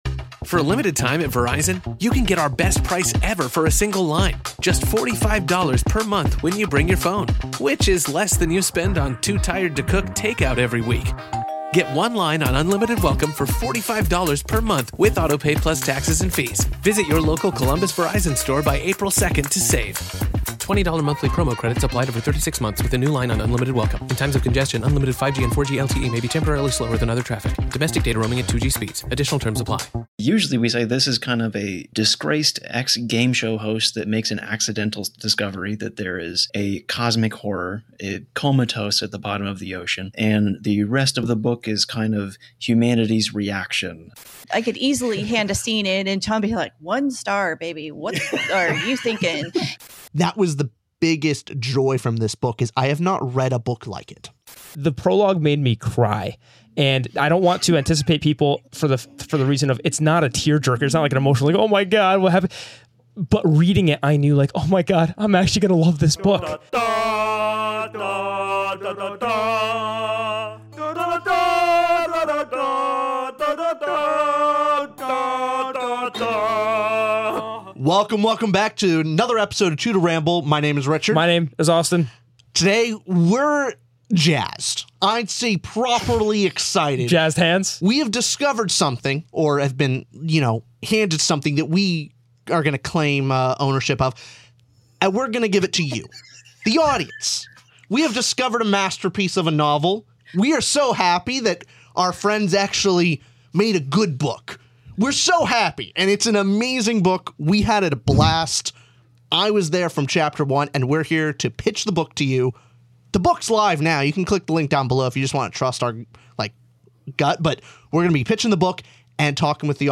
2 guys talking about books (mostly fantasy & sci-fi).